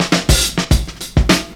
DRUMFILL02-L.wav